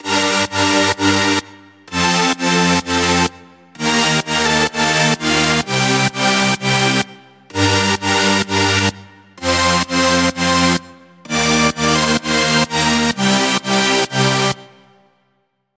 Dabei gab es nur eine Herausforderung: Das, was ihr in folgendem Beispiel hört, ist nicht nur ein Synth, sondern fünf, die gleichzeitig spielen.
Diese „Technik“ ist das so genannte Layering.